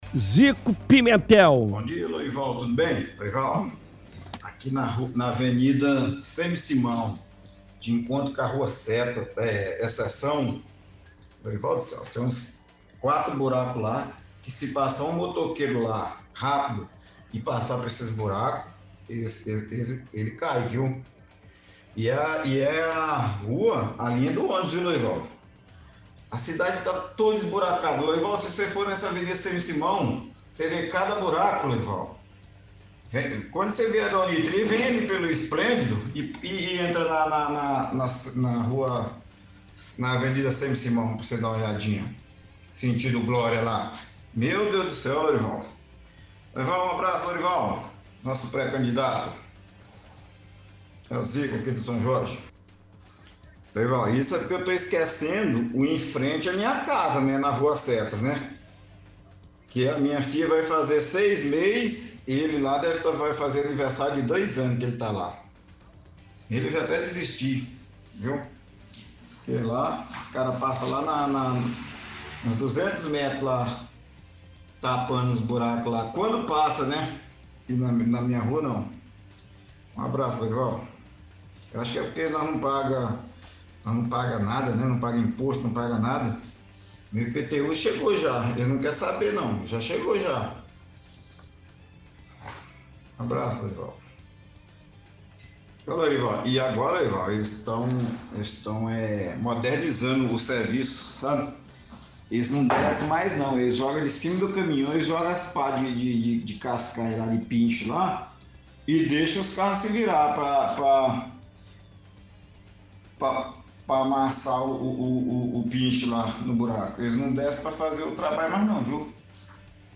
– Ouvinte reclama de buracos nas ruas da cidade.
– Ouvinte lembra reportagem veiculada pela Vitoriosa com cidadã que teve casa derrubada, sendo que deputada falou que iria ajudar, mas depois de ganhar as eleições, falou que não tinha nada que podia ser feito.